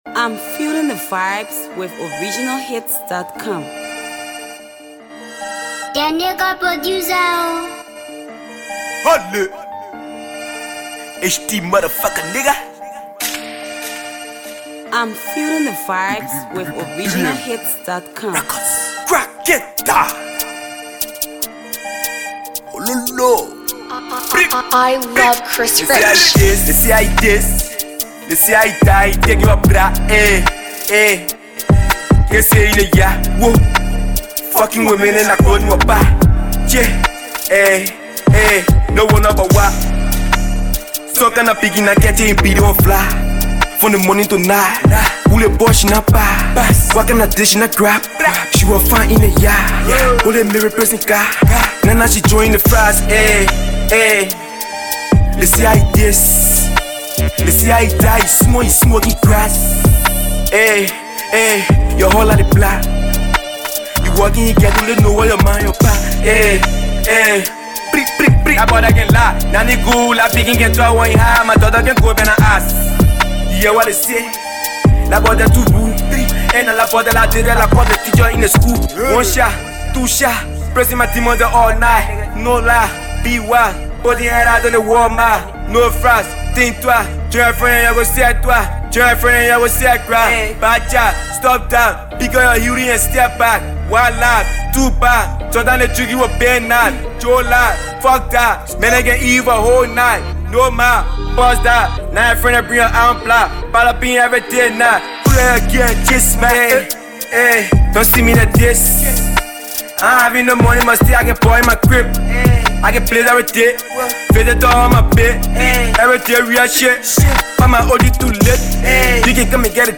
Multi-talented uprising trap artist